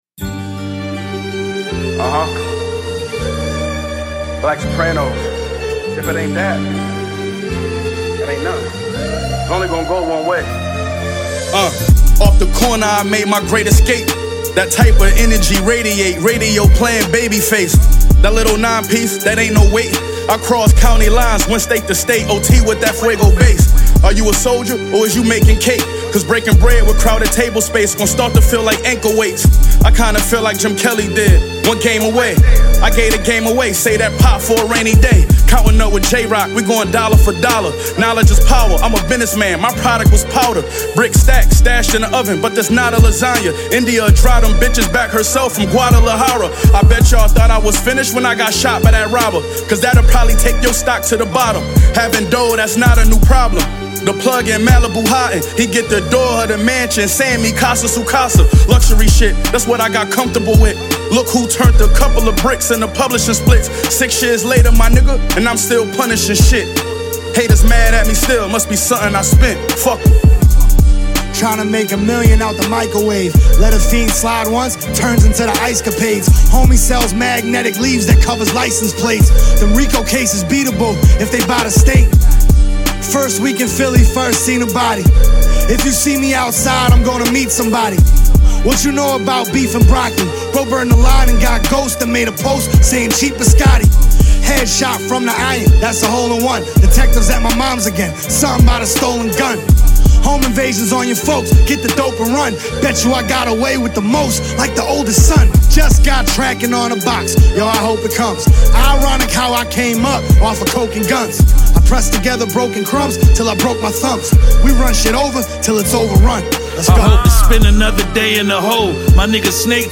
Genre: [Hip-Hop/Rap.